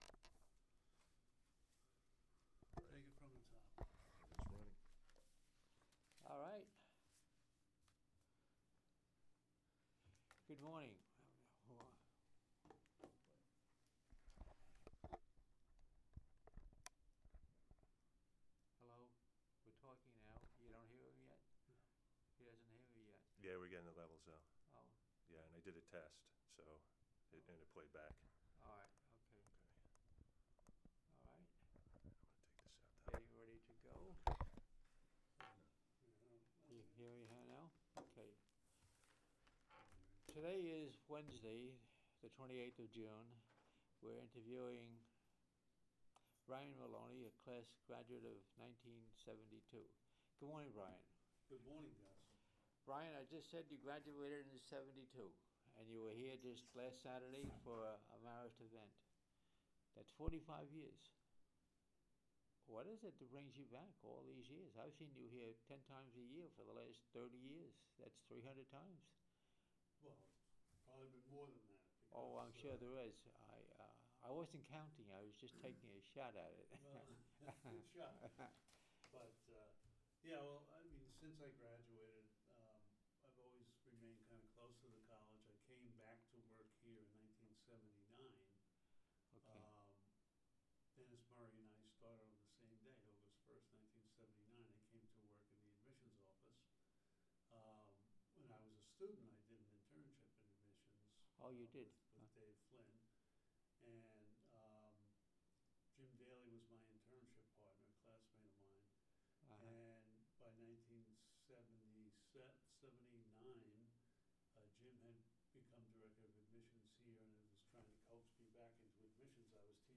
Marist Heritage Oral Histories
Interview